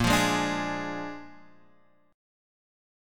A#7 chord